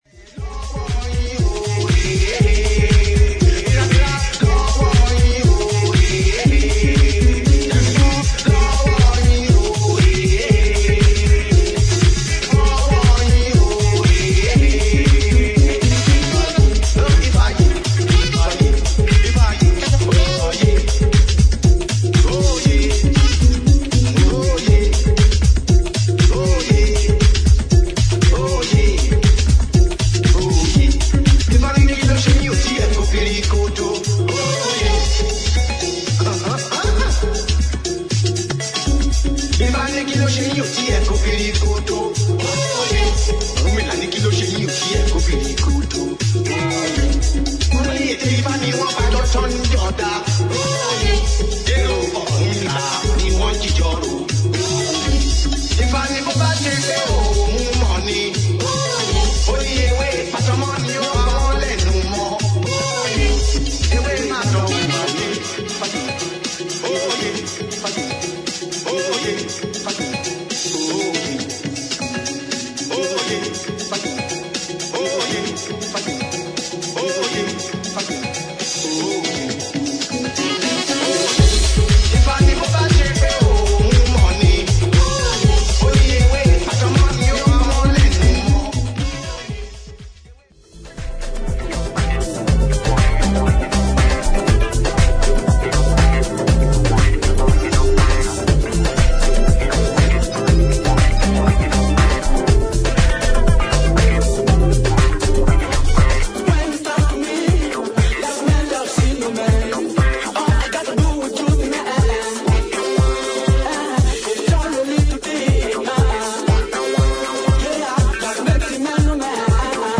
モダンなアフロ・ネタを使い、オリジナルのグルーヴを保ちつつも、ビートを強化しフロア・ライクに仕上げた
前2トラックよりも更にクラブ・ユースなディープ・ハウスへと昇華した